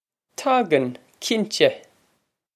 Pronunciation for how to say
Tog-on, kin-teh!
This is an approximate phonetic pronunciation of the phrase.